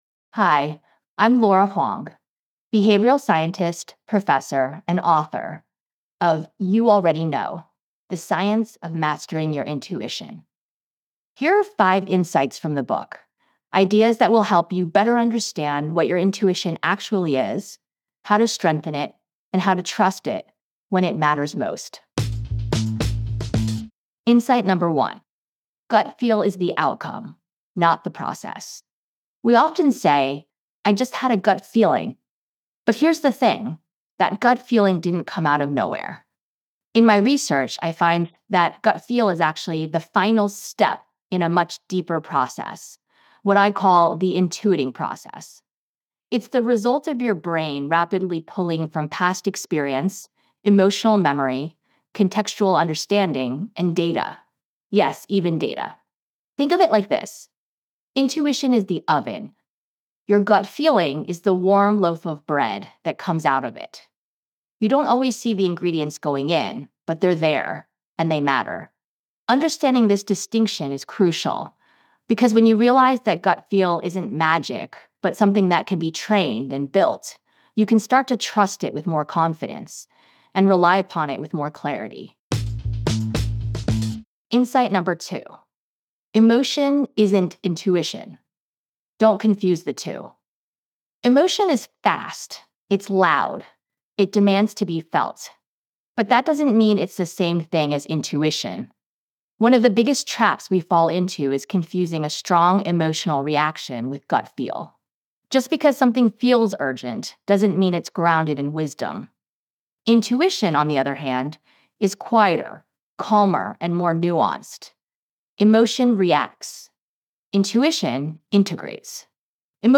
Enjoy our full library of Book Bites—read by the authors!—in the Next Big Idea App: